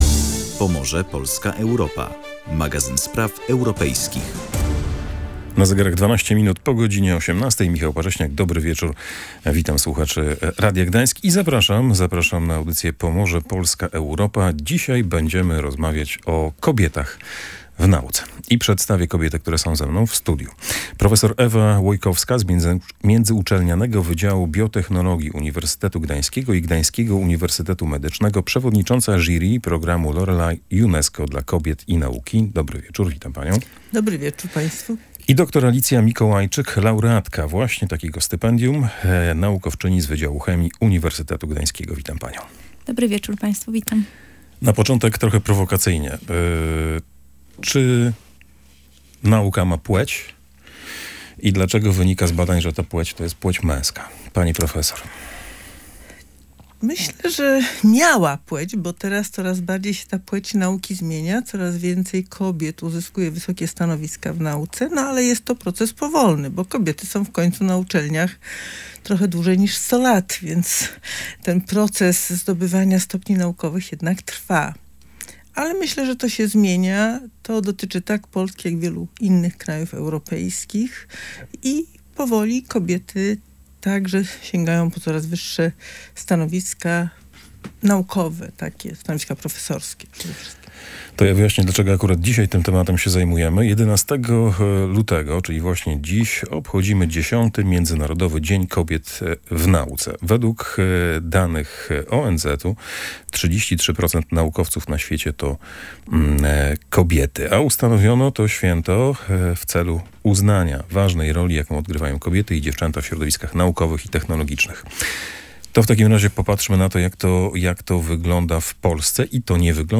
Rozmowę